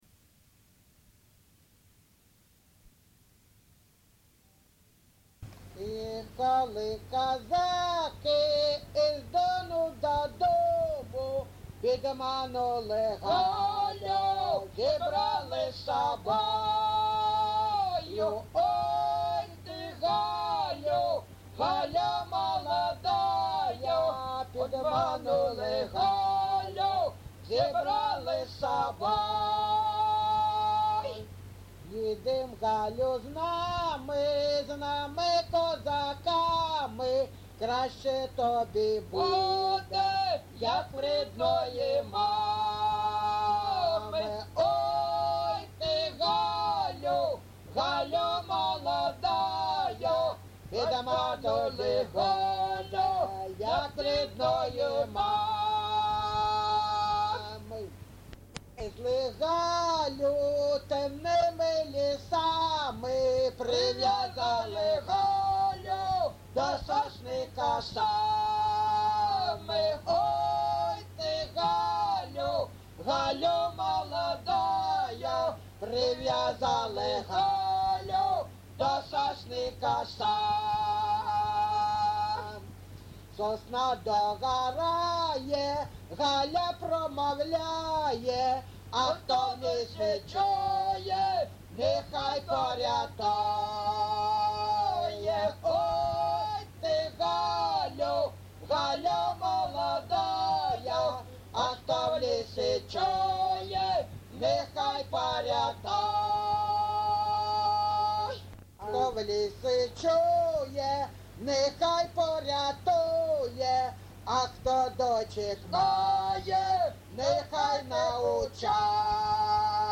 ЖанрПісні з особистого та родинного життя, Козацькі
Місце записус. Григорівка, Артемівський (Бахмутський) район, Донецька обл., Україна, Слобожанщина